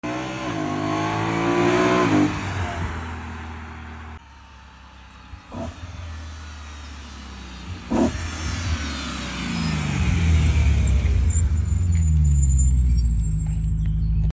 E92 M3 Gruppe M Intake Sound Effects Free Download